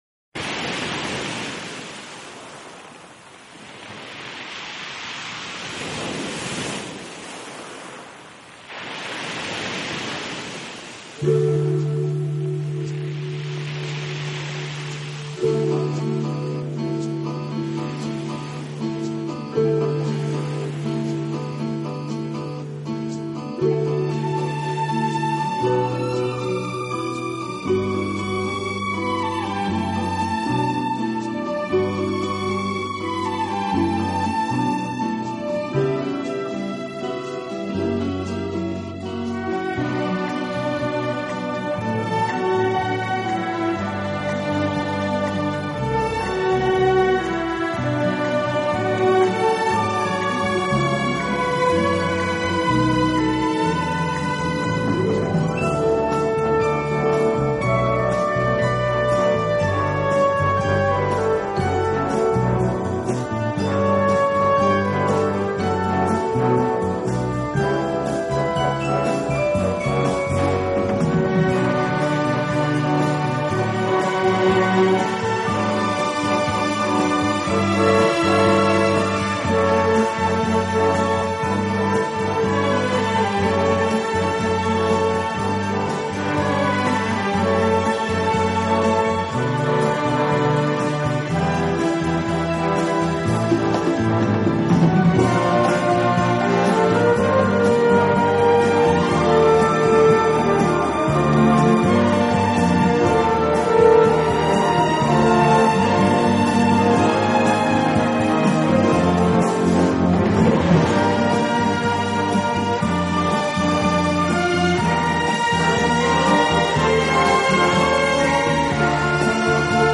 【纯音乐】
器配置，使每首曲子都呈现出清新的自然气息。